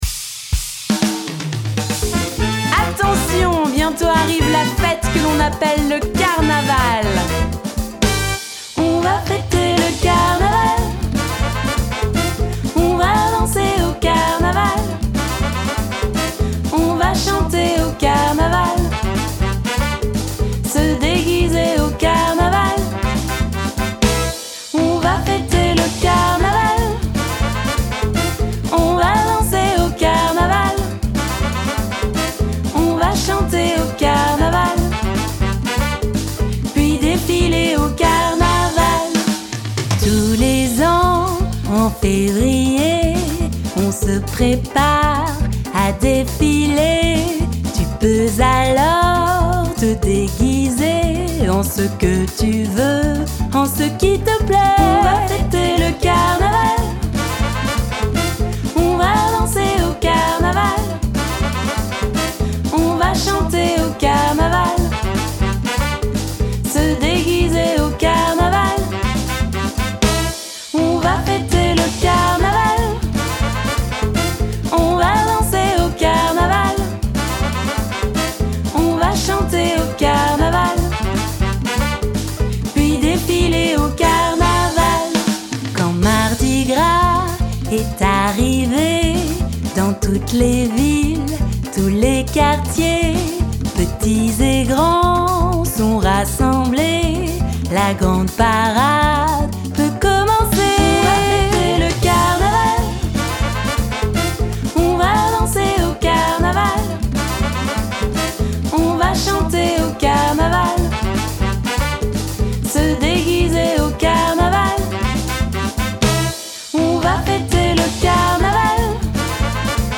Cette chanson énergique